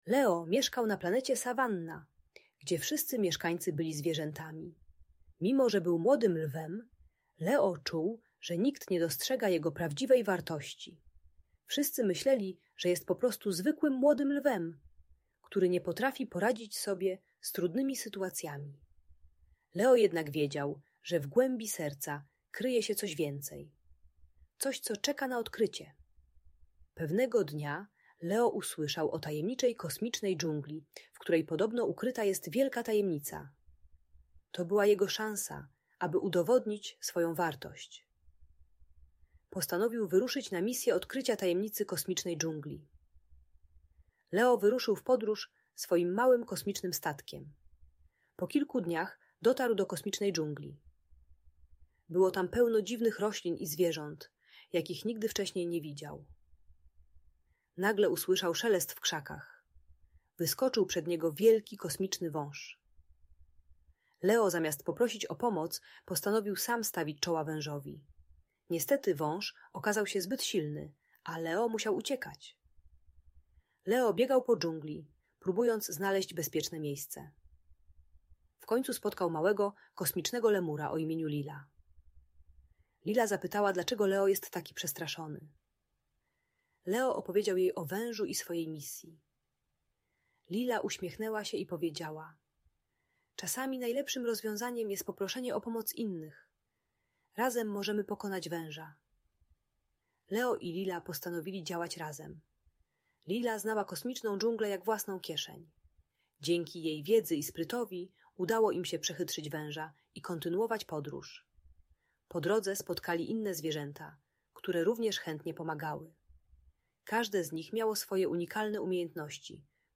Leo i tajemnica kosmicznej dżungli - Bunt i wybuchy złości | Audiobajka